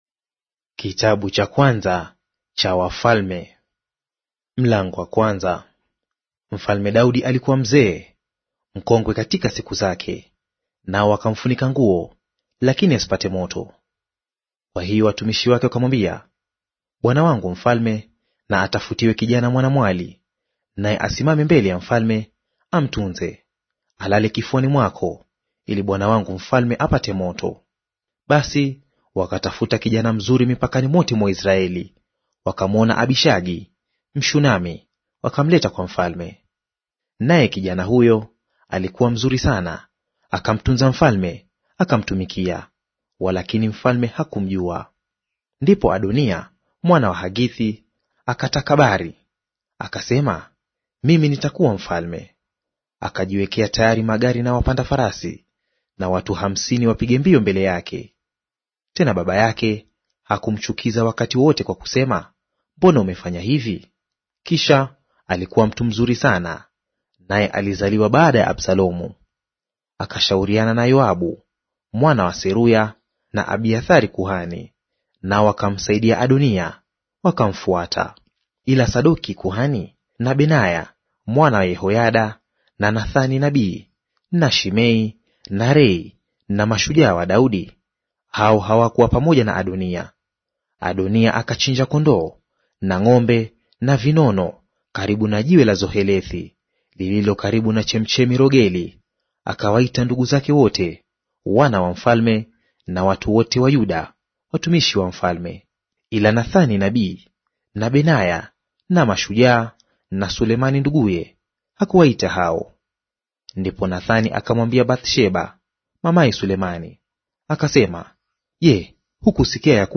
Audio reading of 1 Wafalme Chapter 1 in Swahili